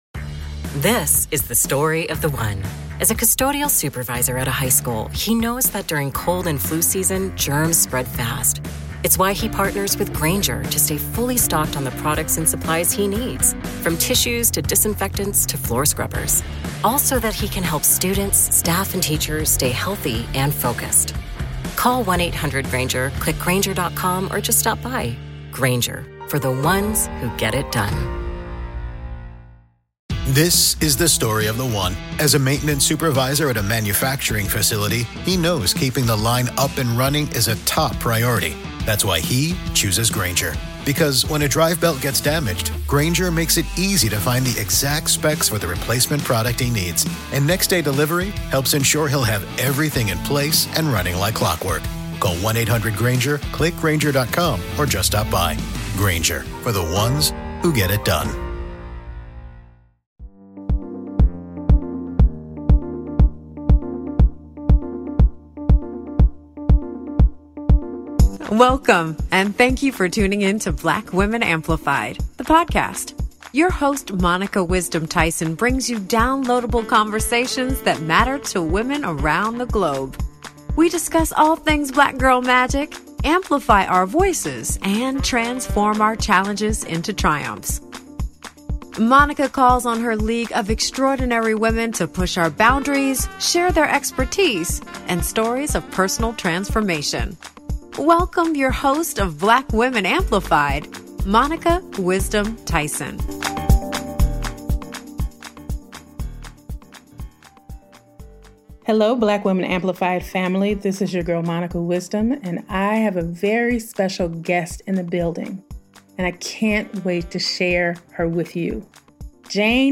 For seven seasons, Black Women Amplified has centered the voices, stories, and lived experiences of Black women across the country, offering intimate, story-rich conversations that honor our brilliance, power, and cultural impact.